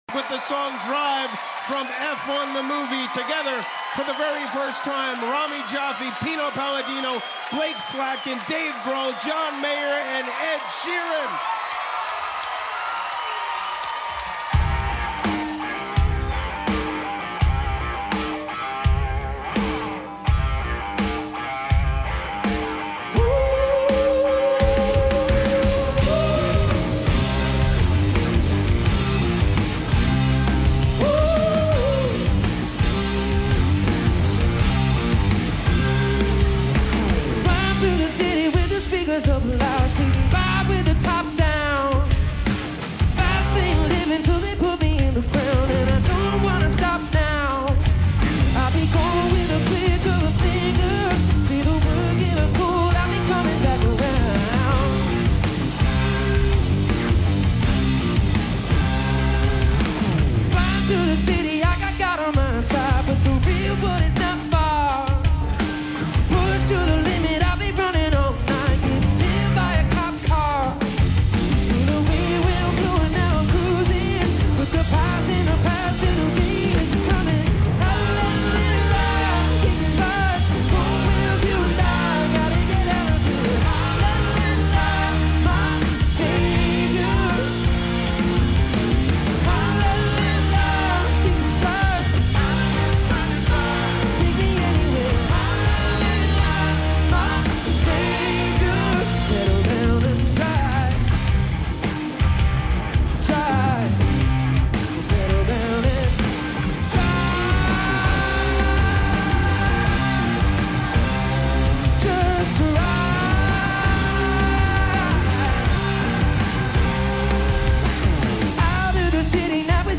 It moves with purpose rather than flash.